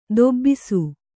♪ dobbisu